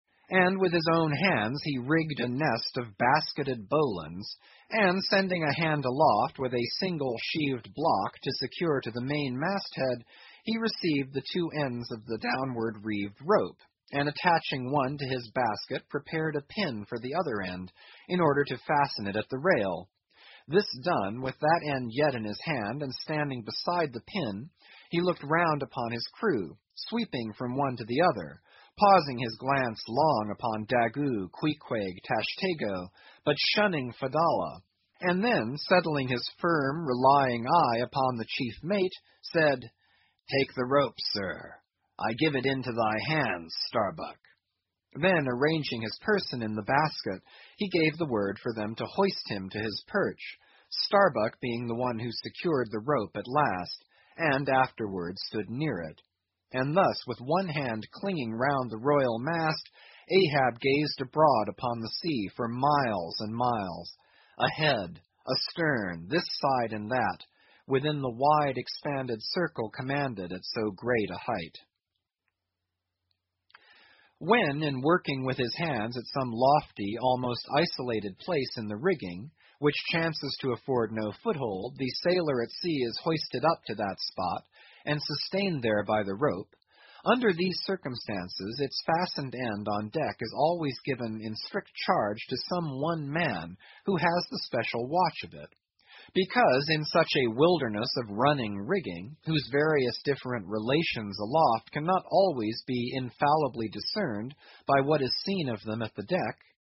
英语听书《白鲸记》第991期 听力文件下载—在线英语听力室